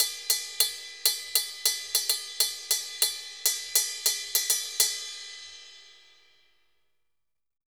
Ride_Samba 100_2.wav